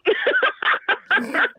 Naughty Laugh!